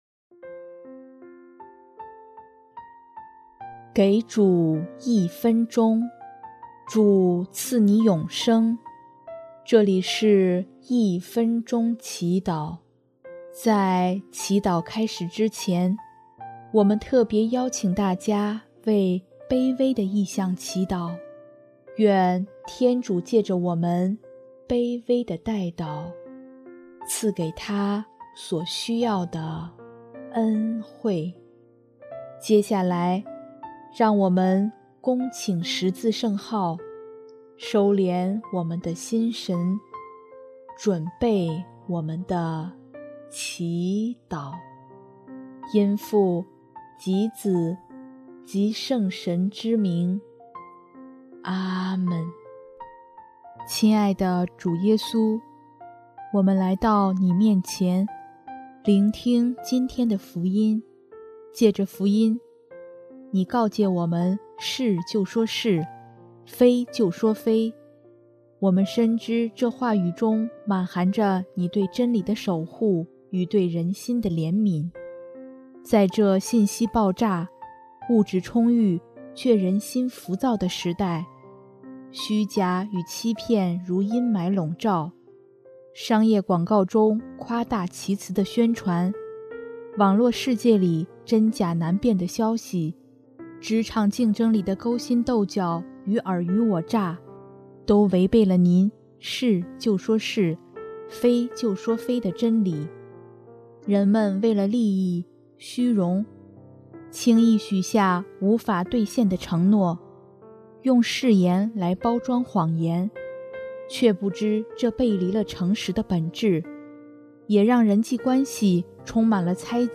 音乐：第三届华语圣歌大赛参赛歌曲《你是我的希望》